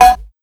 150UKCOWB1-R.wav